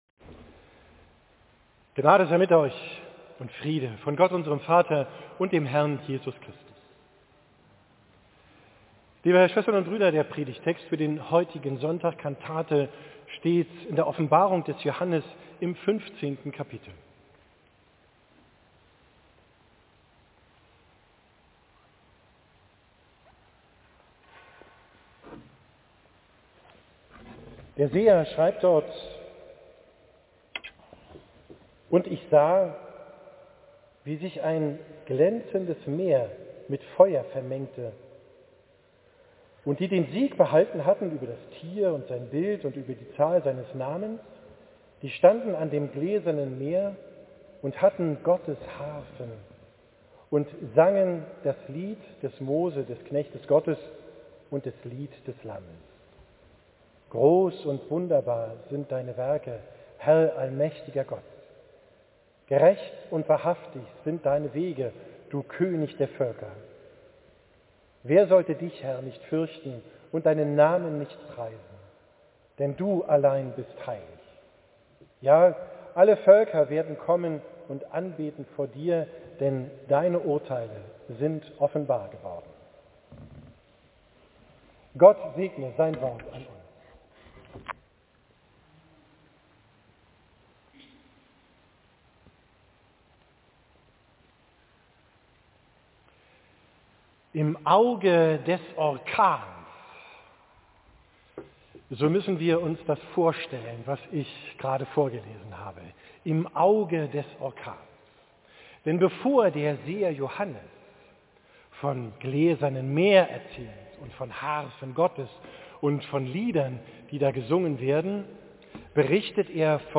Predigt am Sonntag Kantate, 28. IV 2024